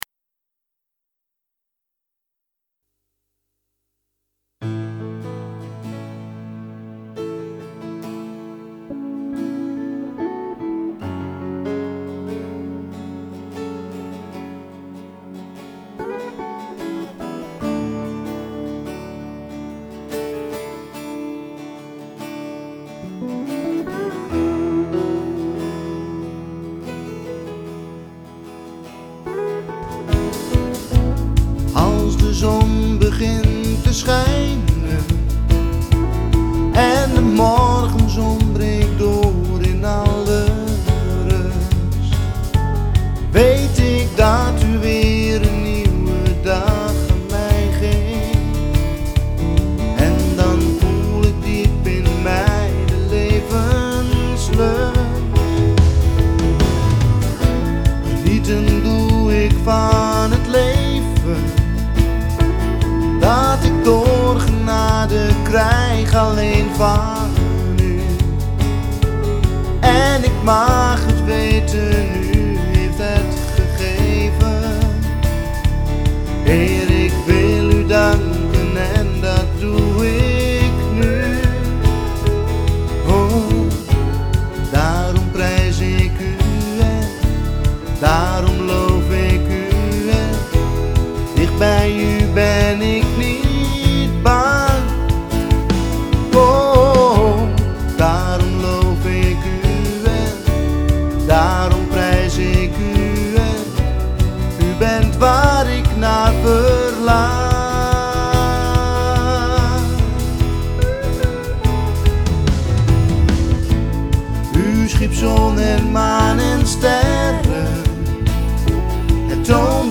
die me helpt met de hoge partijen te zingen